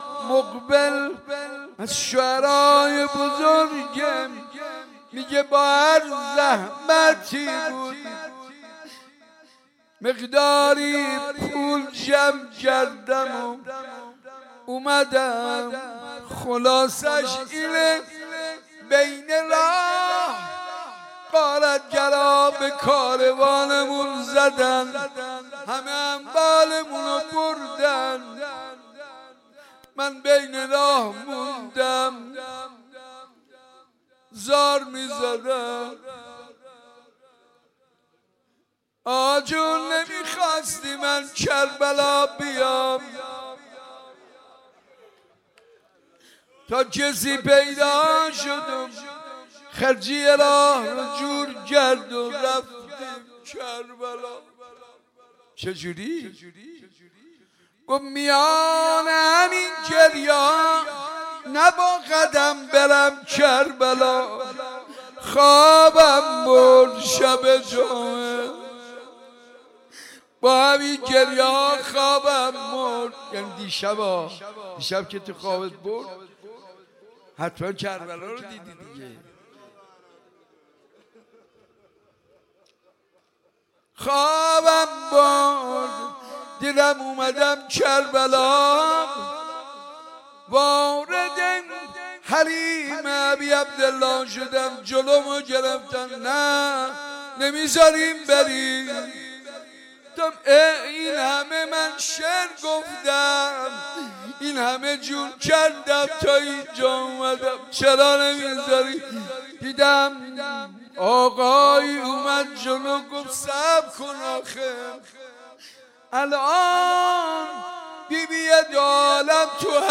مداحی شب اول محرم 1399